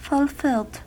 Ääntäminen
Ääntäminen US Haettu sana löytyi näillä lähdekielillä: englanti Käännös Adjektiivit 1. realizzato {m} 2. appagato {m} Fulfilled on sanan fulfil partisiipin perfekti.